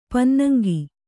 ♪ pannaŋgi